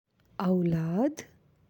(aulad)
aulad.aac